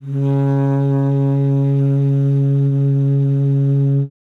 42e-sax01-c#3.wav